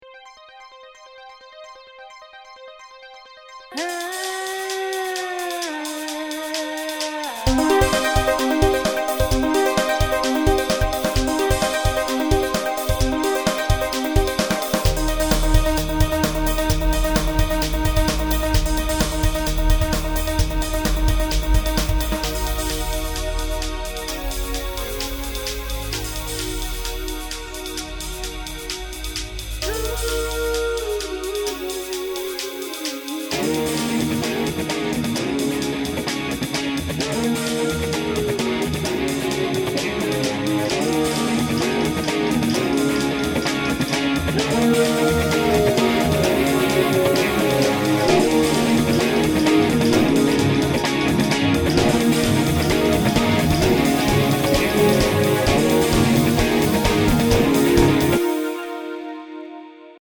Link June 12, 2013 Compositions , Online 1 Comment New Piece: Dancefloor Daydream New (Short) Piece: Dancefloor Daydream features percussion, synthesizers, vocals, guitar, and horns. stay tuned for lots more new content coming, soon 🙂